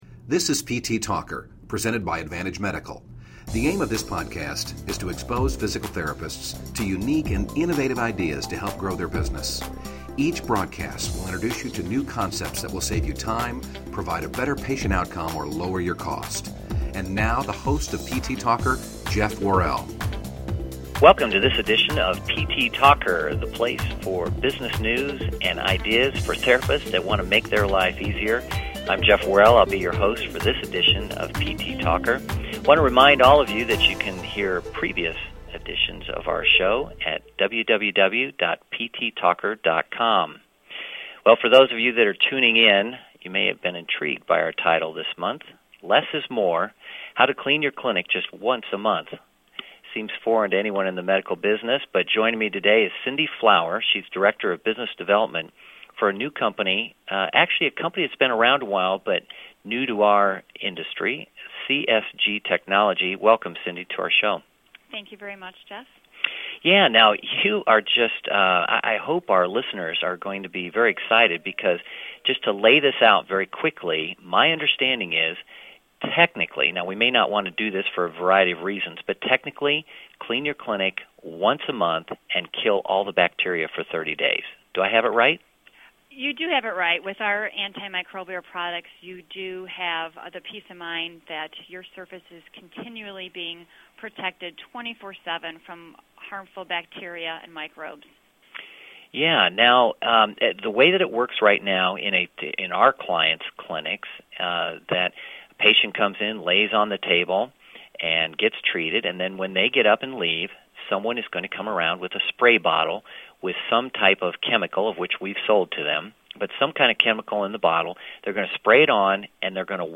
csginterview1.mp3